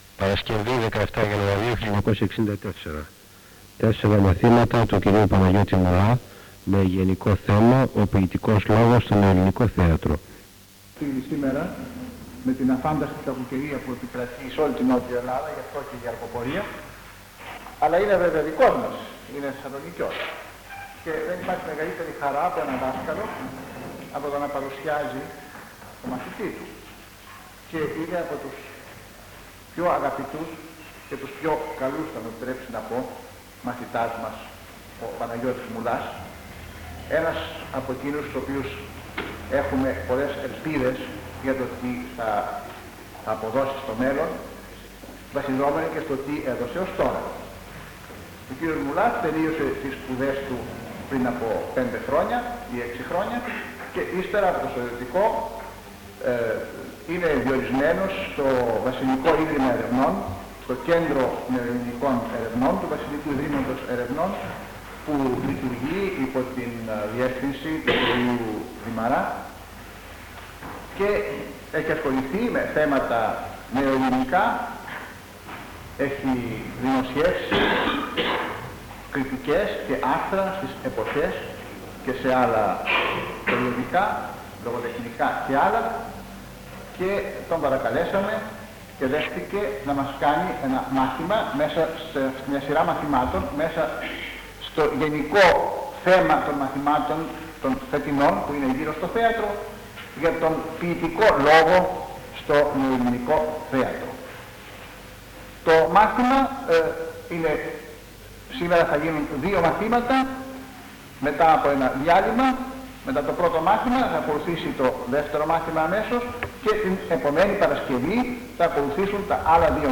Εξειδίκευση τύπου : Εκδήλωση
Περιγραφή: Κύκλος Μαθημάτων